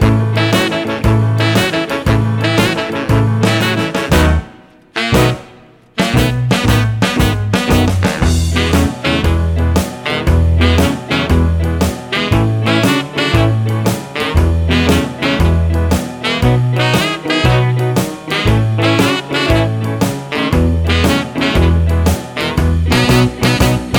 no Backing Vocals Rock 'n' Roll 2:30 Buy £1.50